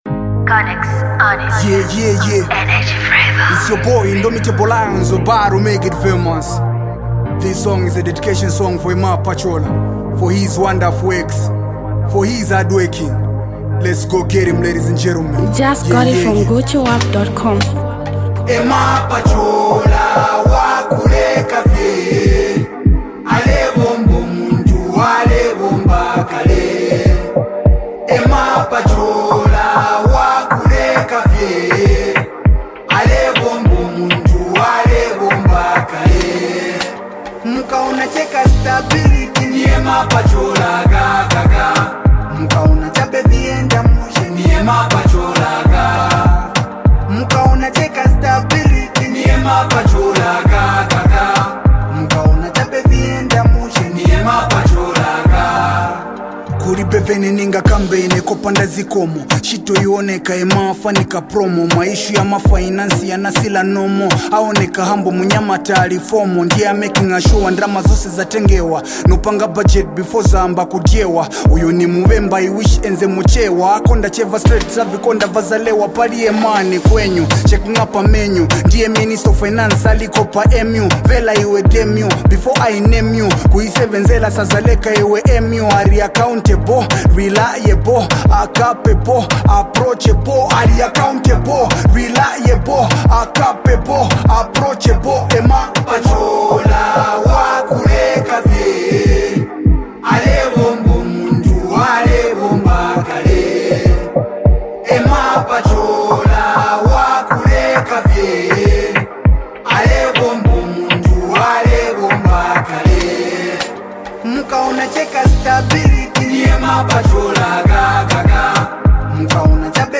political anthem